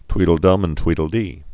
(twēdl-dŭm ən twēdl-dē)